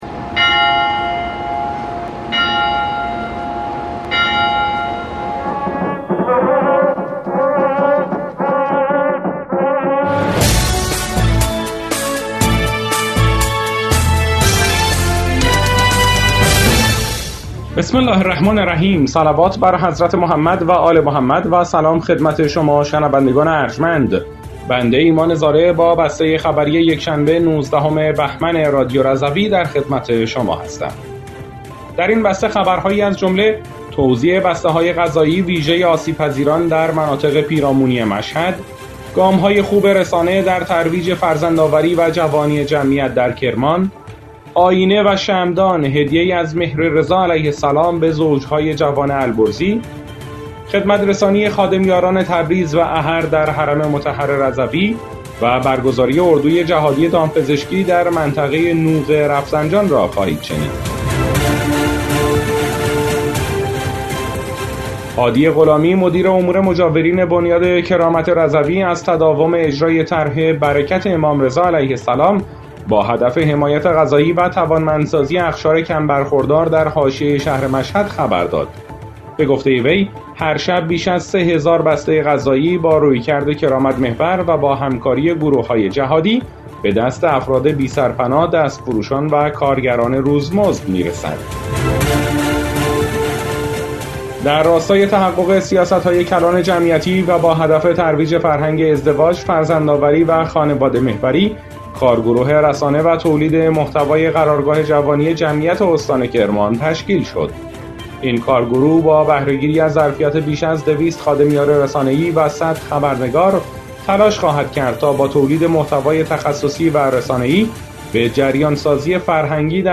بسته خبری ۱۹ بهمن ۱۴۰۴ رادیو رضوی؛